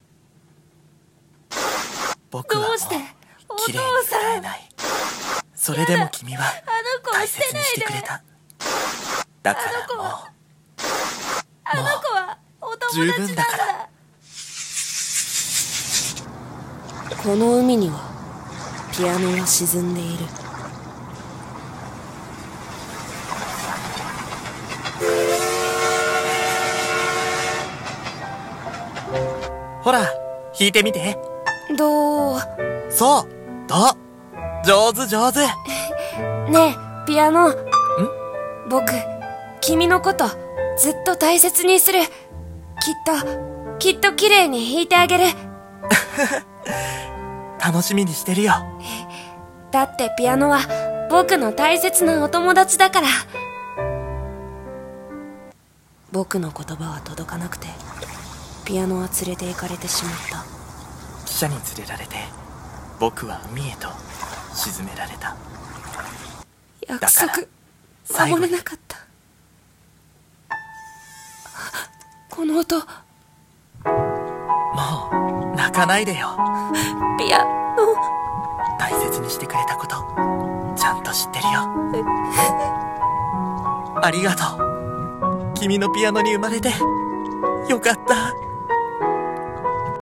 二人声劇】海落とされたピアノ。